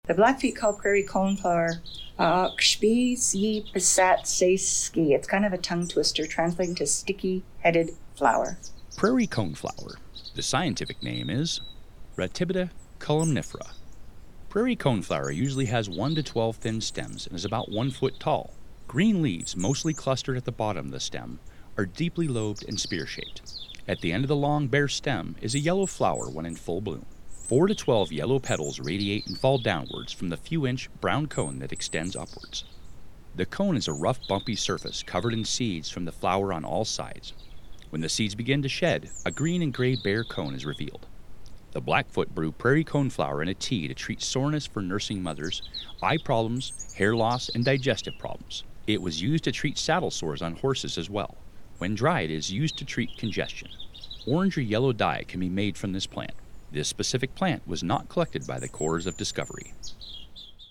Narration: